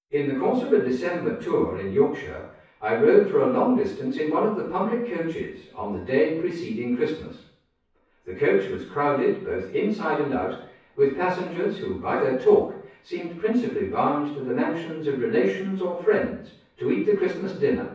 If the reverb time can be adjusted to be approximately 1 second, the predicted spoken word audibility is simulated:
spoken sentences